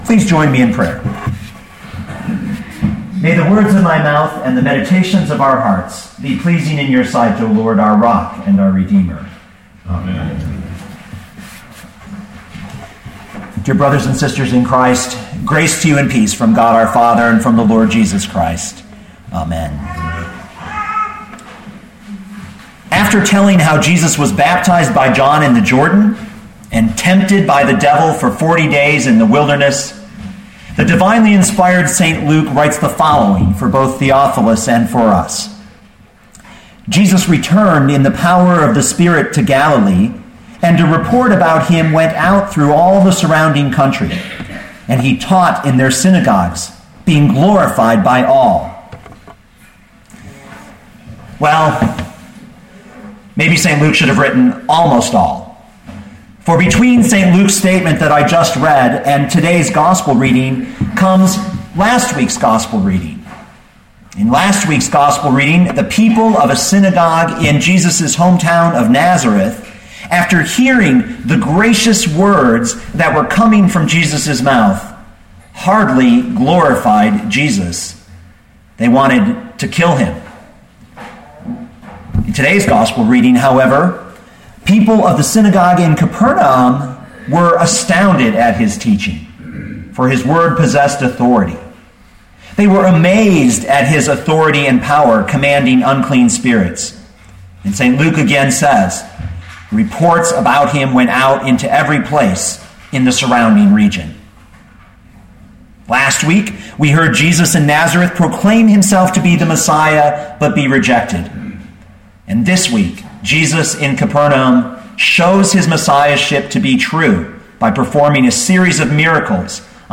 2013 Luke 4:31-44 Listen to the sermon with the player below, or, download the audio.